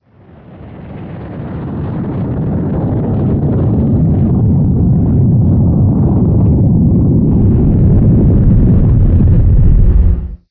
spaceship_fadein.wav